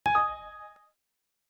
جلوه های صوتی
دانلود صدای تعجب ویندوز xp از ساعد نیوز با لینک مستقیم و کیفیت بالا